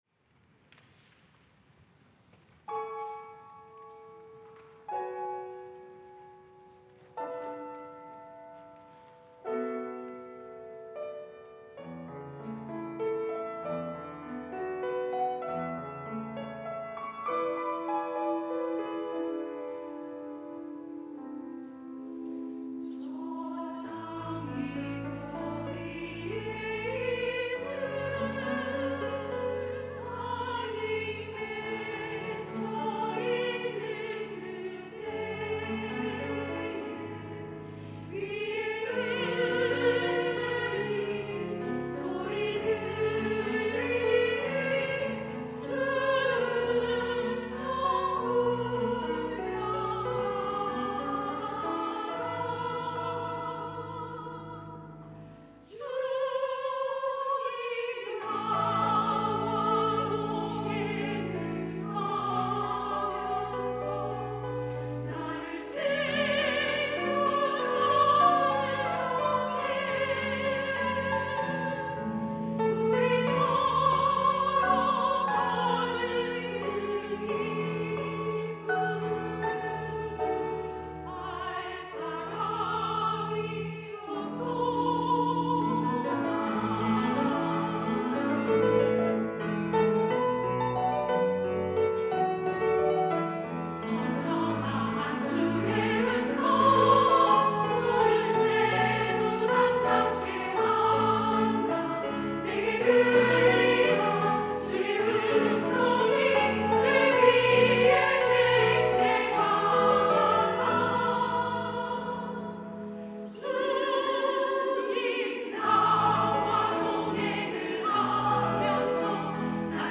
찬양대 여전도회